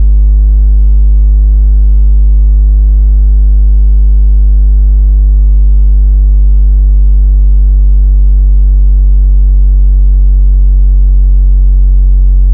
808s
SUB BOOM79.wav